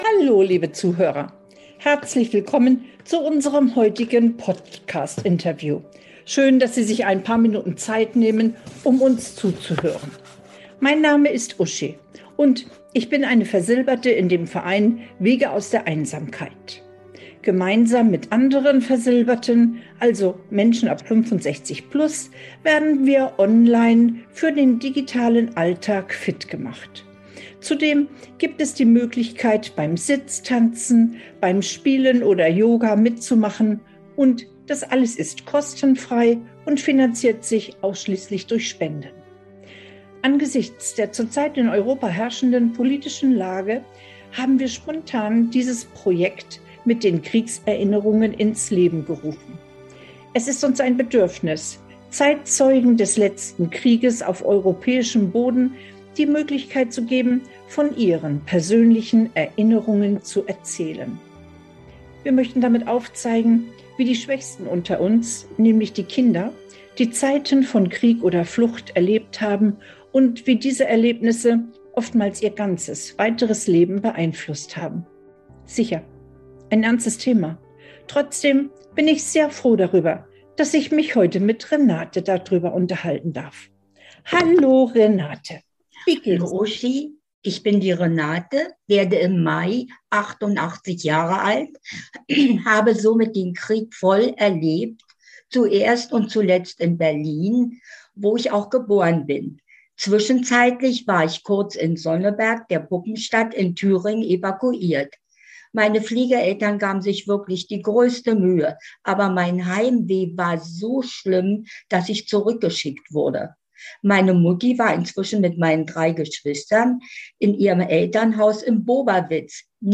Zeitzeugen